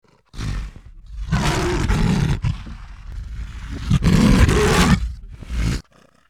Tiếng sư tử gầm: